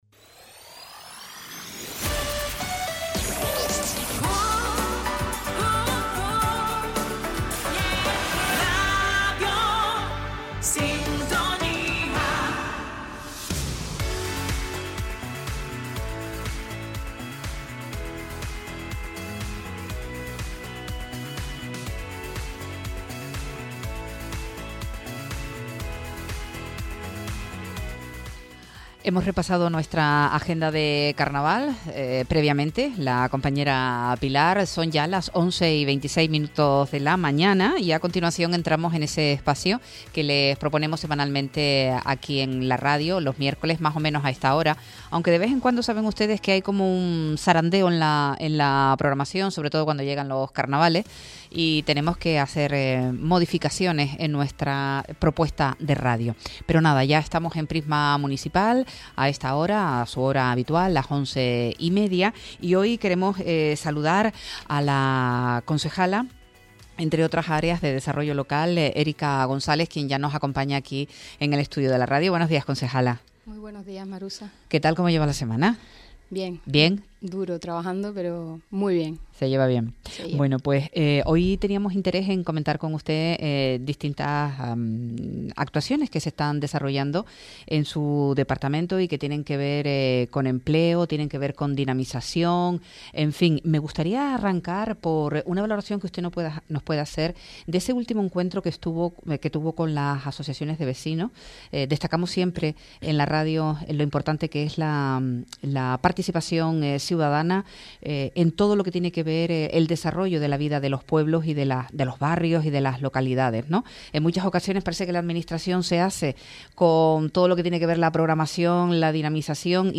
Prisma Municipal con Érica González, concejala de Desarrollo Local – 19.03.25 Deja un comentario